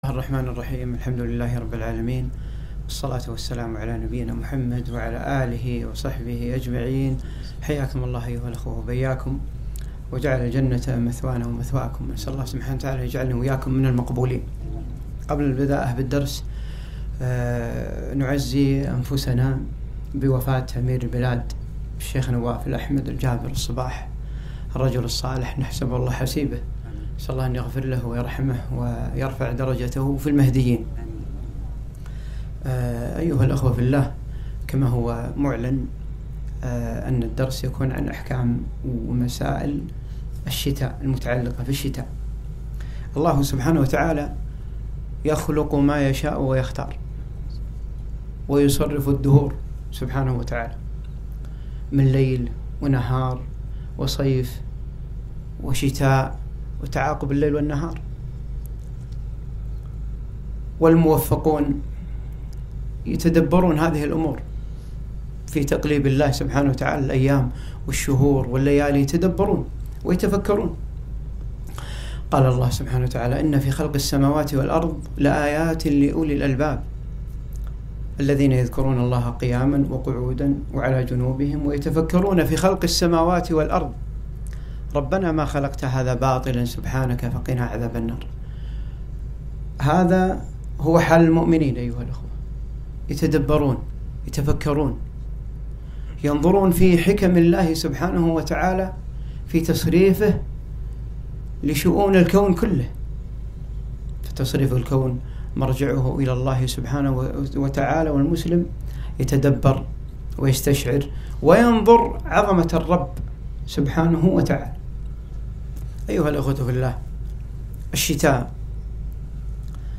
محاضرة - مسائل تتعلق بالشتاء